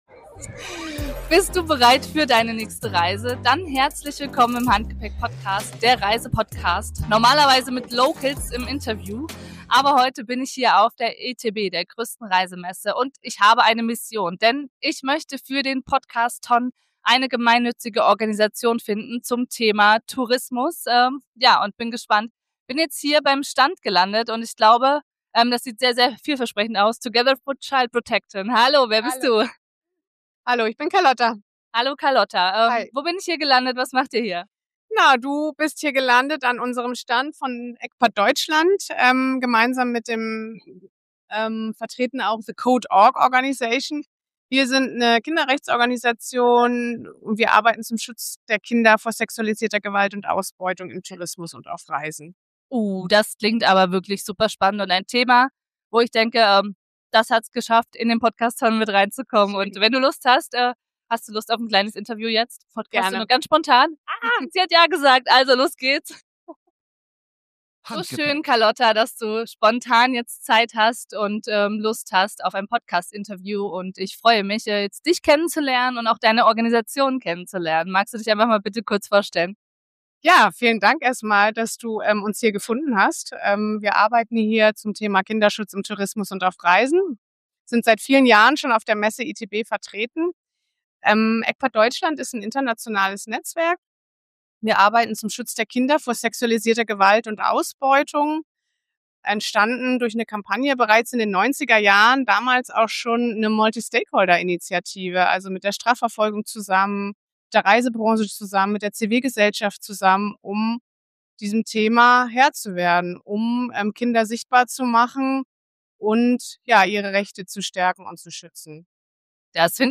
In dieser Podcastfolge spreche ich mit ECPAT, einer internationalen Organisation, die sich weltweit für den Schutz von Kindern vor Ausbeutung einsetzt.